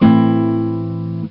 Ac Guitar Min Sound Effect
ac-guitar-min.mp3